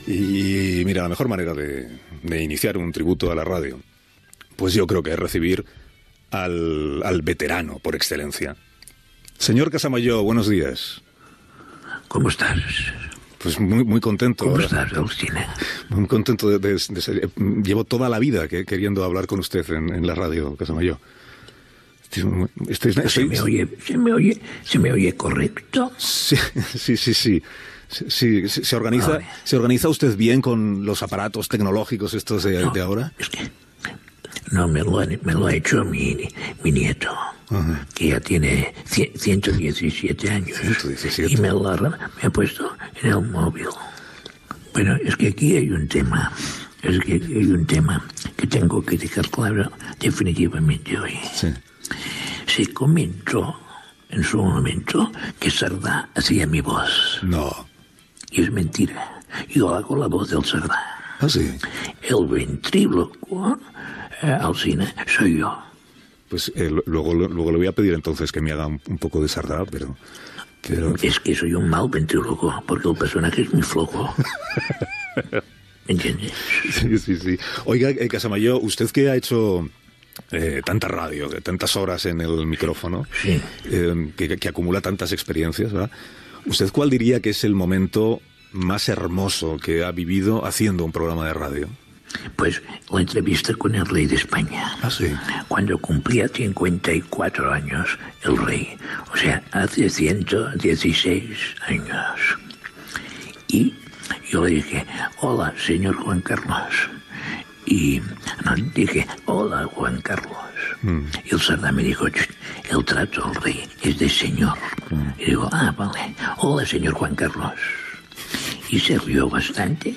Fragment d'una entrevista al Sr. Casamajor (Xavier Sardà)
Info-entreteniment